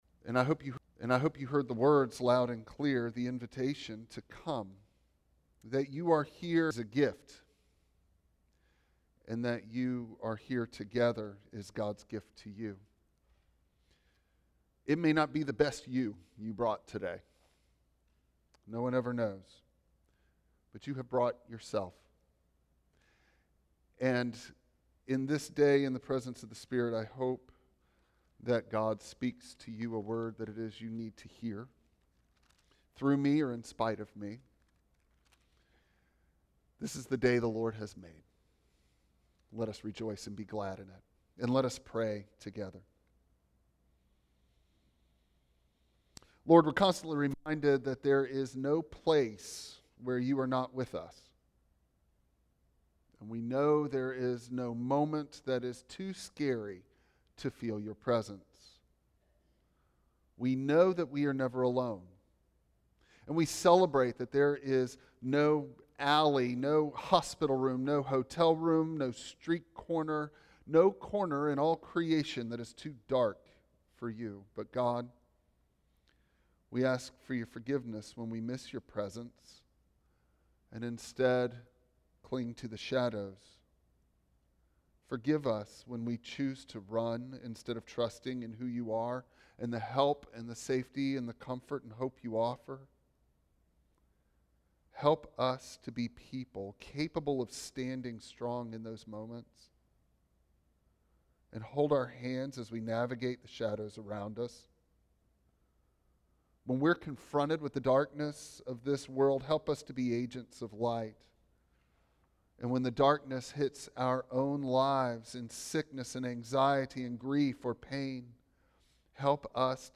Passage: Matthew 6:25-34 Service Type: Traditional Service